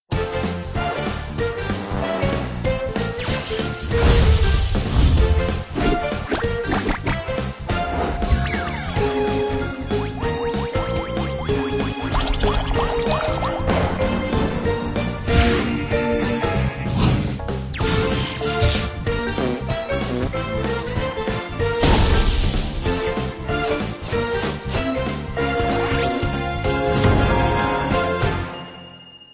un petit générique :